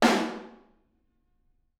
R_B Snare 01 - Room.wav